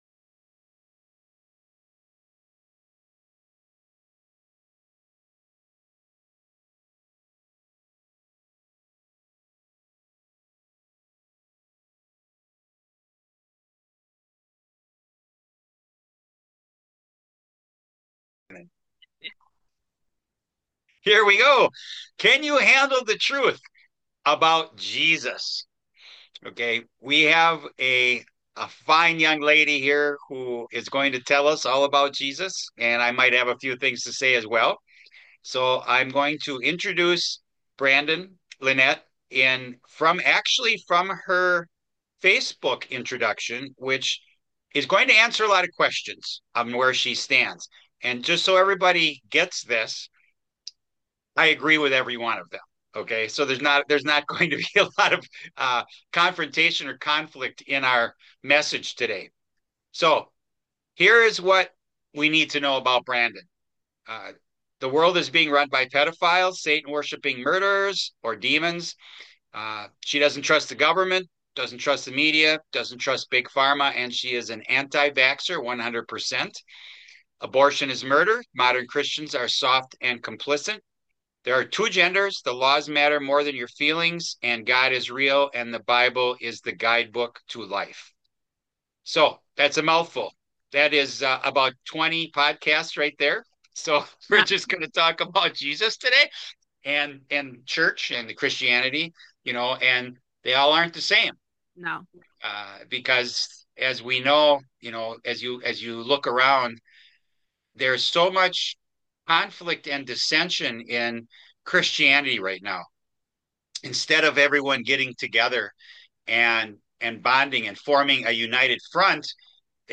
a bold, faith-centered conversation about leaving New Age spirituality, embracing Jesus, and building the kind of disciplined faith that actually changes your life.